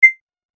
/ F｜システム電子音 / F-02 ｜システム2 さらにシンプルな電子音 カーソル移動などに
システム電子音(シンプル-短) 400 02 選択 004